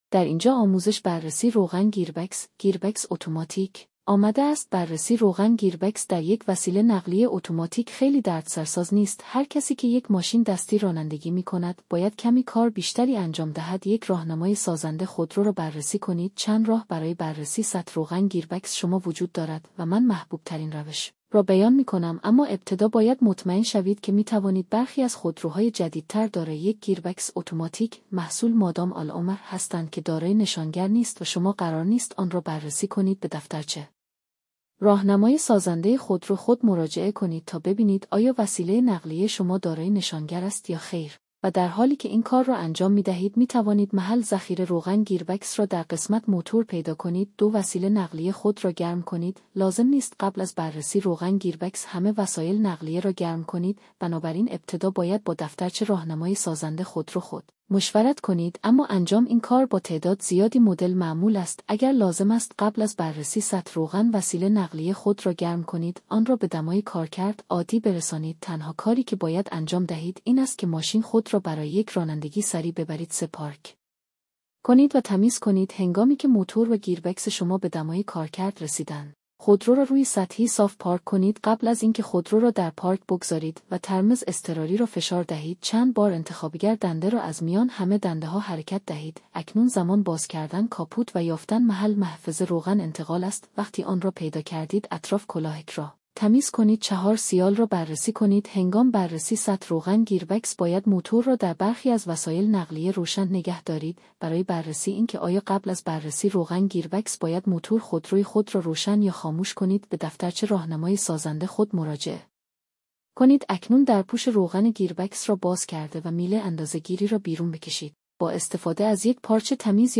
صوت هوش مصنوعی آموزش بررسی سطح روغن گیربکس: